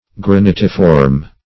Search Result for " granitiform" : The Collaborative International Dictionary of English v.0.48: Granitiform \Gra*nit"i*form\, a. [Granite + -form.]
granitiform.mp3